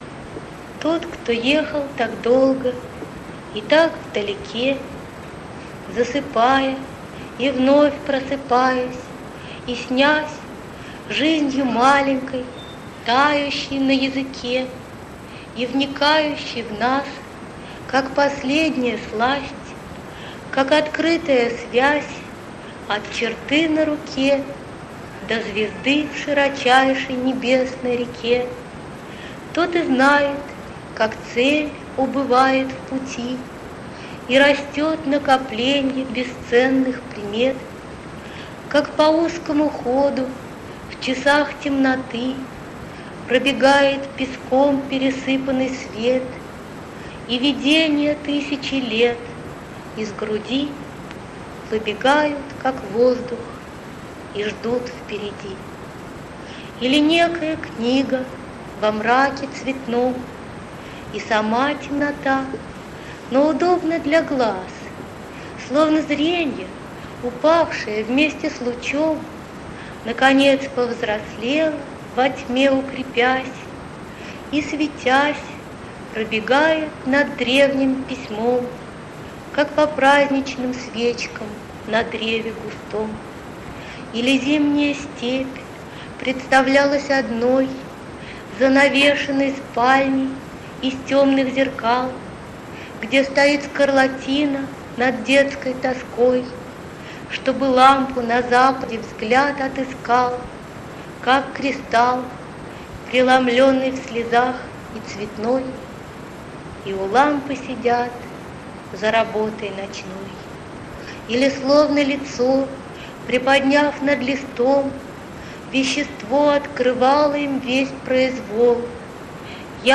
1. «Ольга Седакова – Путешествие волхвов (читает автор)» /